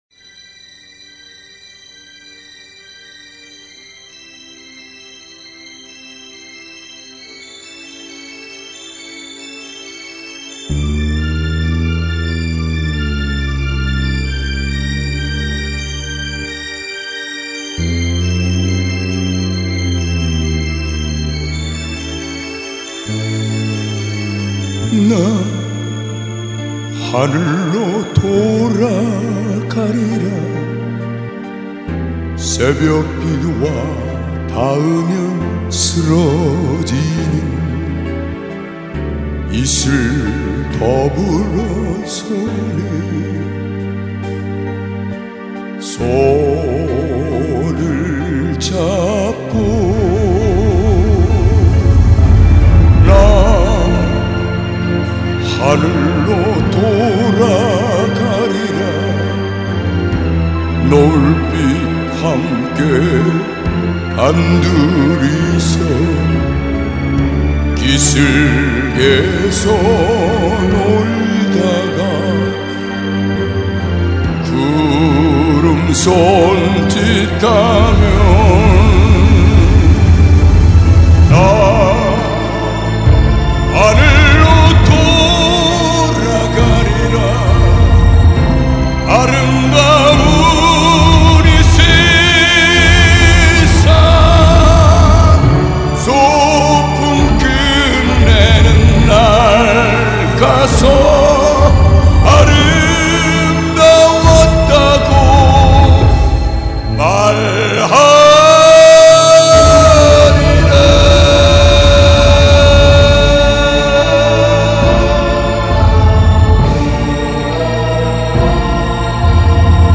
기타공연